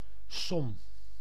Ääntäminen
IPA: /ˈtɑːl/